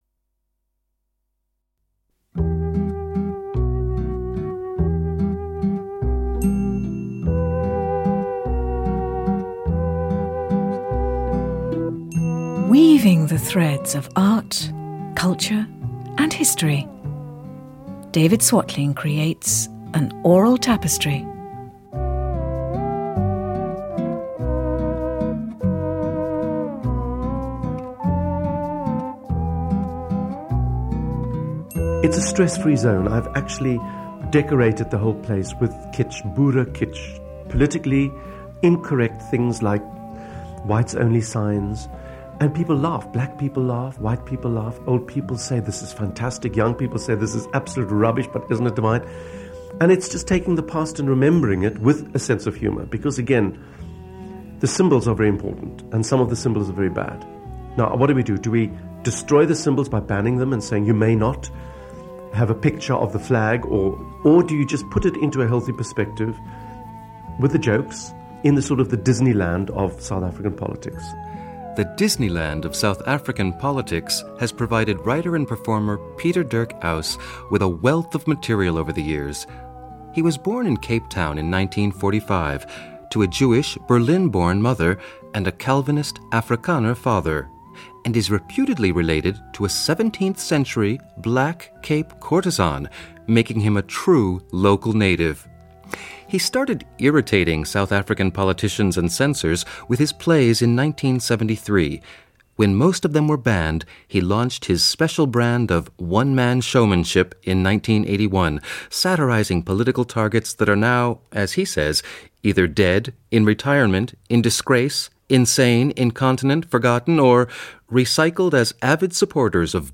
During each of these visits, he was interviewed by Radio Netherlands. We also hear highlights of some of his performances.